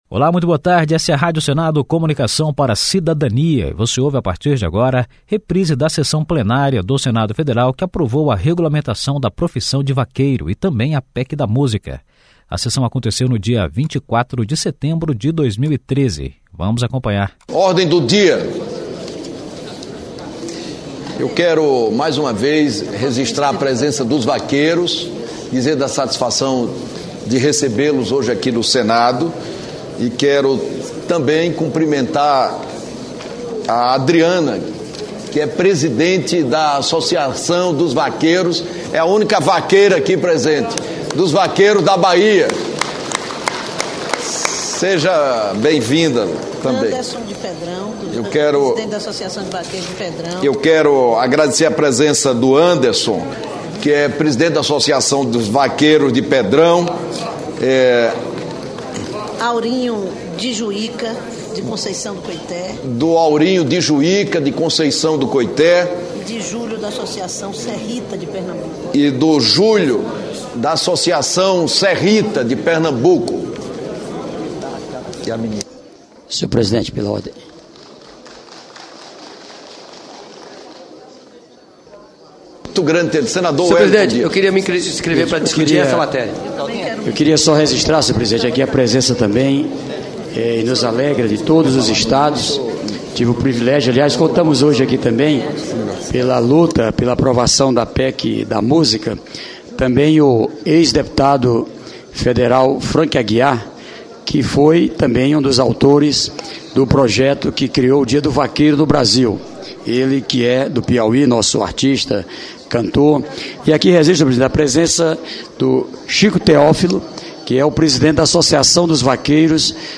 Sessão de aprovação da PEC da Música e do PL da profissão de vaqueiro (1)